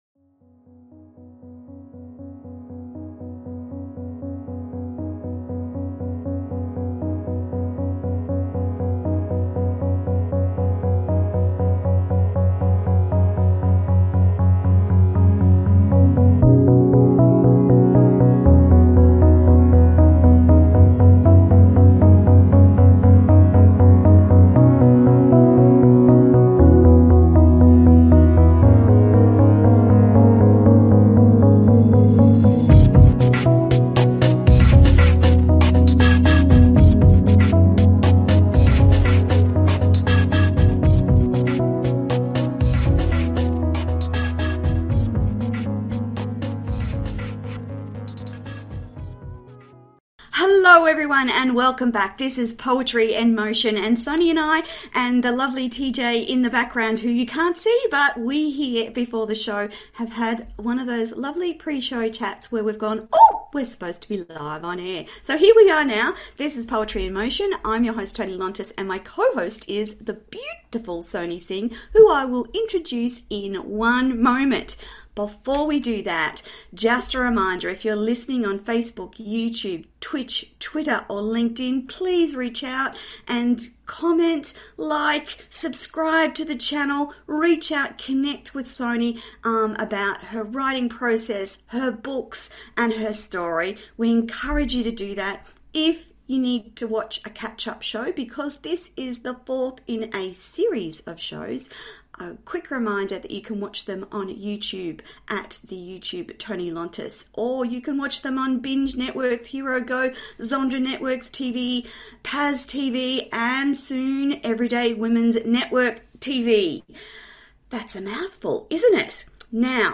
Talk Show Episode, Audio Podcast, Poetry In Motion and Lonely Dove, writing novels vs poetry, the process of writing on , show guests , about Lonely Dove,writing novels vs poetry,the process of writing, categorized as Arts,Literature,Business,Entertainment,Philosophy,Self Help